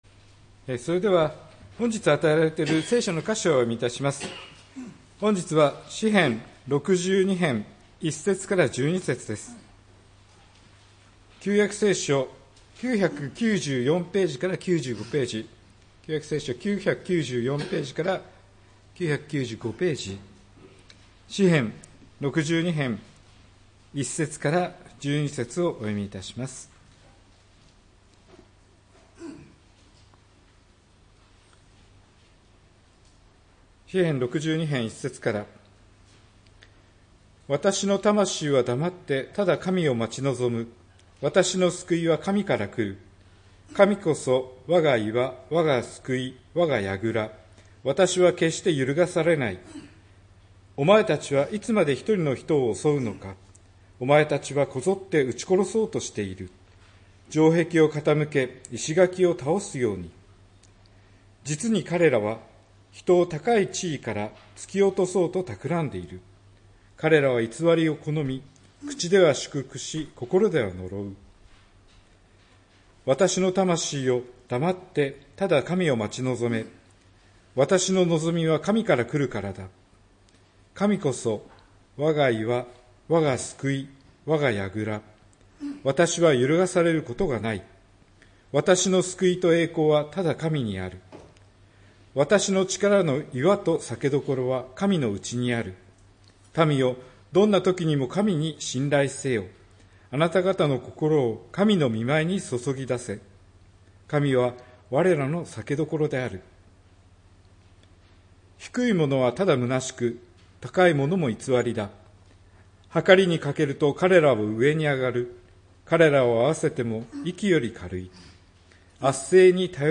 礼拝メッセージ「神を待ち望む」(12月29日）